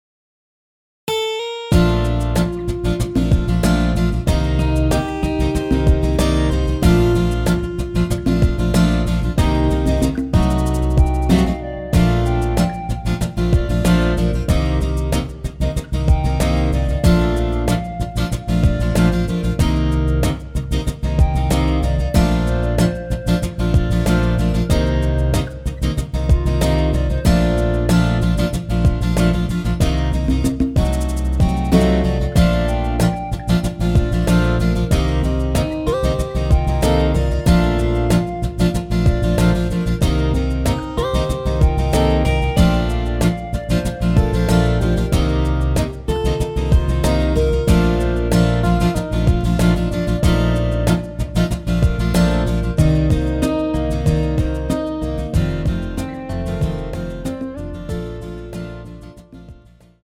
원키에서(-1)내린 멜로디 포함된 MR 입니다.(미리듣기 참조)
Bb
앞부분30초, 뒷부분30초씩 편집해서 올려 드리고 있습니다.